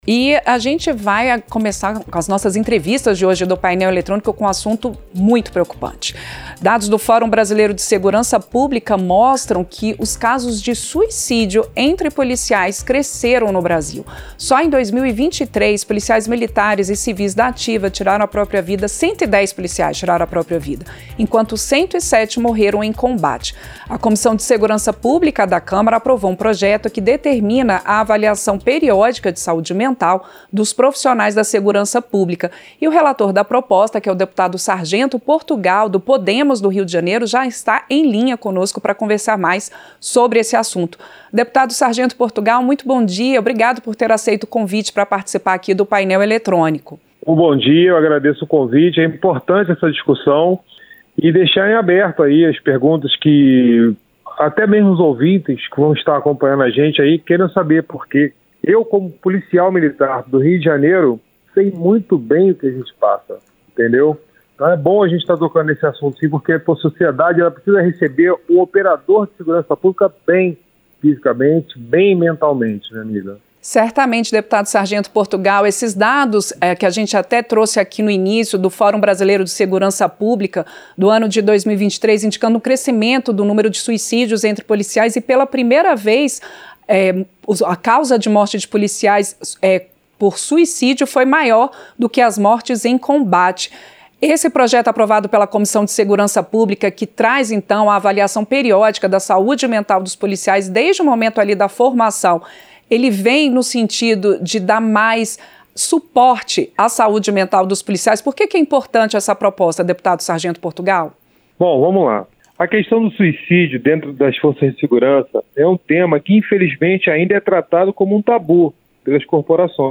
• Entrevista - Dep. Sargento Portugal (Podemos-RJ)
Programa ao vivo com reportagens, entrevistas sobre temas relacionados à Câmara dos Deputados, e o que vai ser destaque durante a semana.